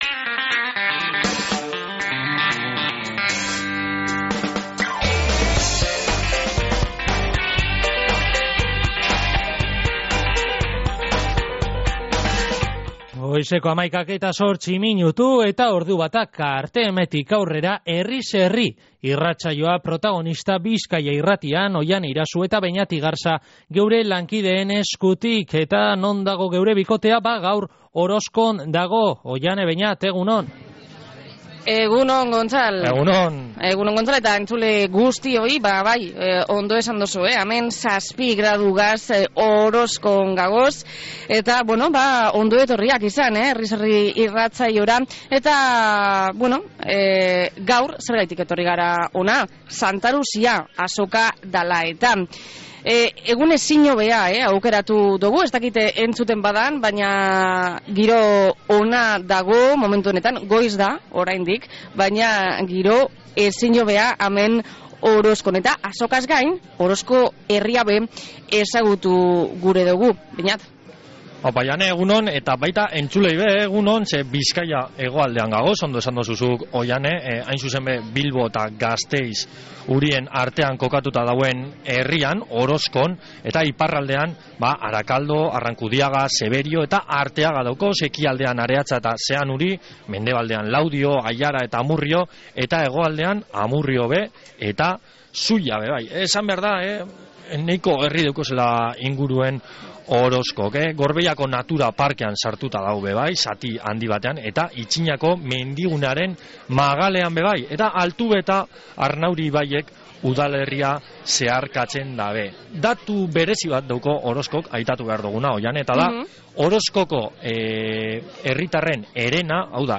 Herriz Herri Orozkoko Santa Luzia azokatik